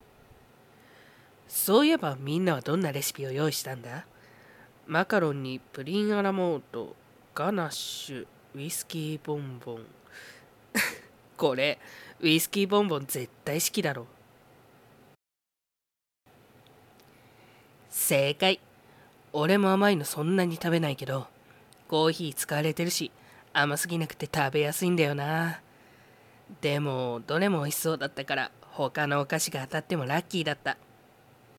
2月声劇